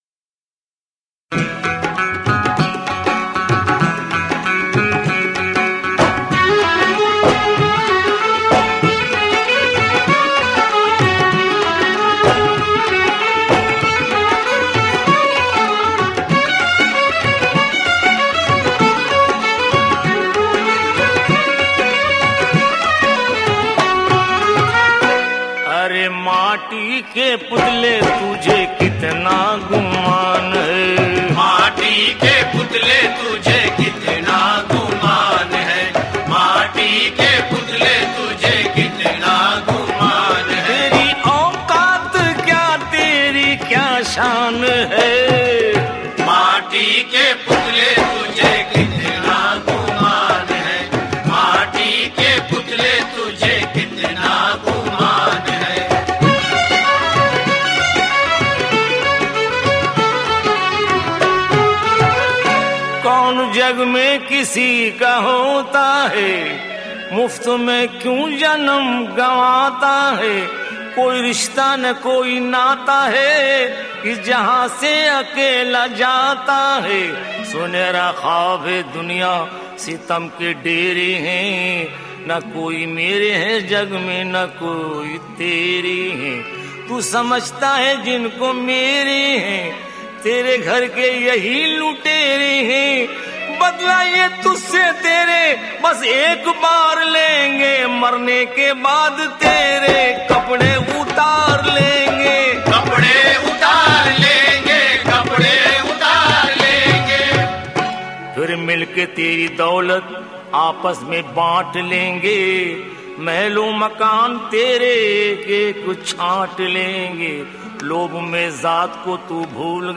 Urdu Qawwali MP3